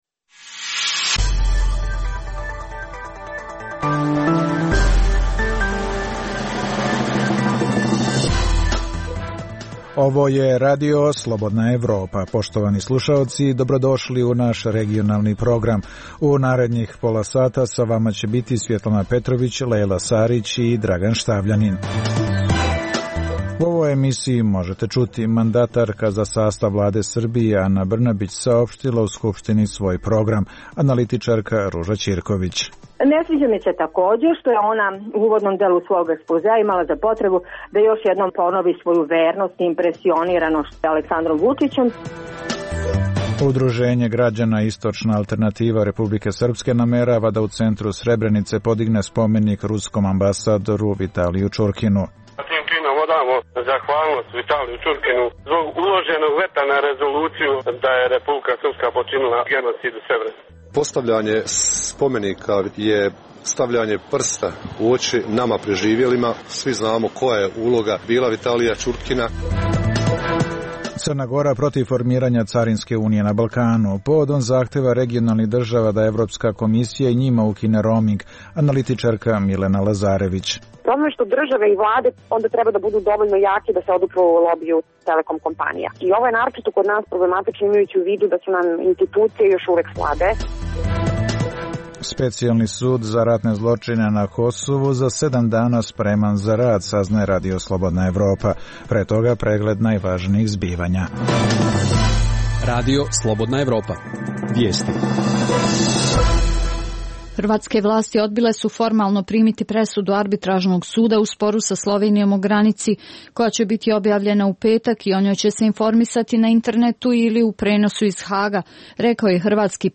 Preostalih pola sata emisije sadrži analitičke priloge iz svih zemalja regiona i iz svih oblasti, od politike i ekonomije, do kulture i sporta. Reportaže iz svakodnevnog života ljudi su svakodnevno takođe sastavni dio “Dokumenata dana”.